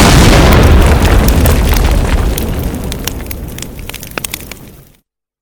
grenade-explode.ogg